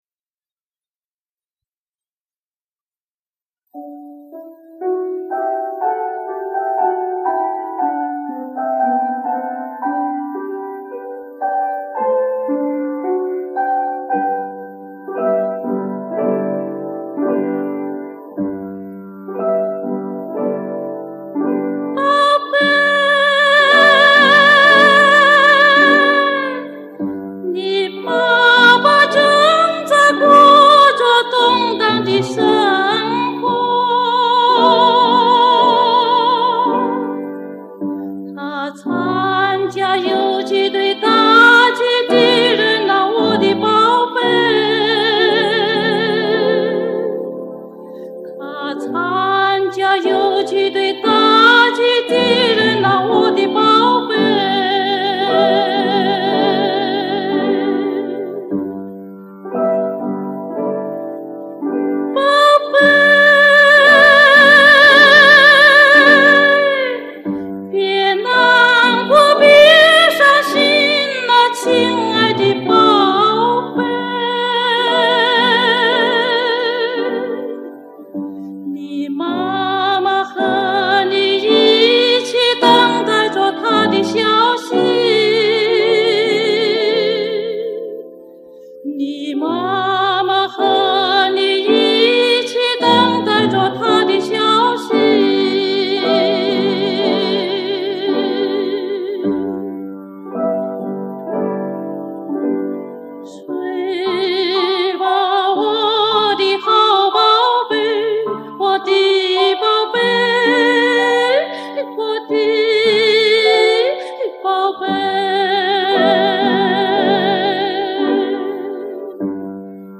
著名女高音歌唱家
本专辑所有音频均为历史录音